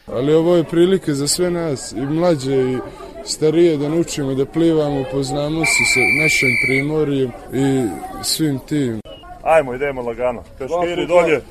Atmosfera u odmaralištu